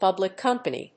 públic cómpany
音節pùblic cómpany